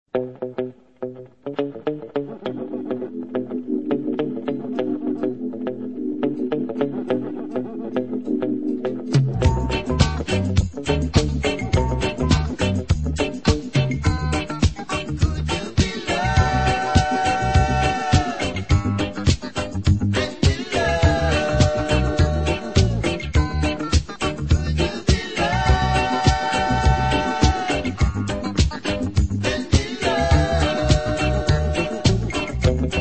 • Reggae Ringtones